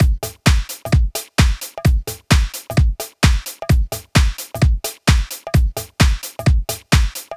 Downtown House
Drum Loops 130bpm